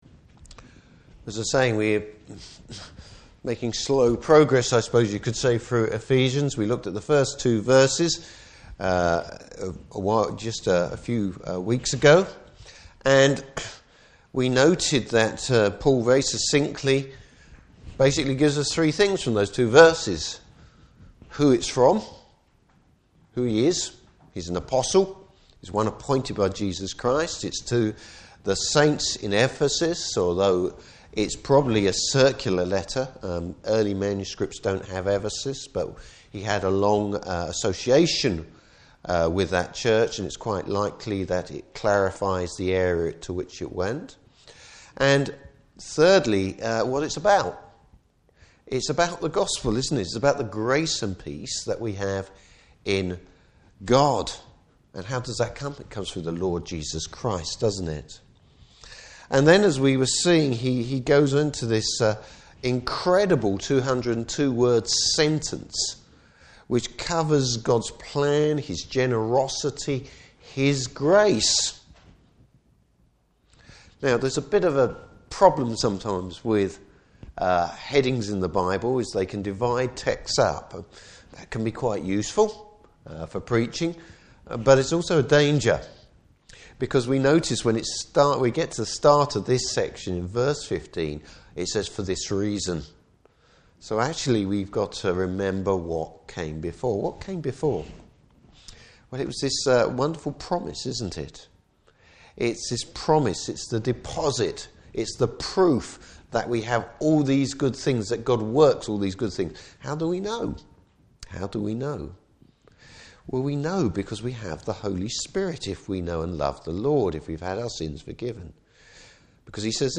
Service Type: Morning Service Bible Text: Ephesians 1:15-23.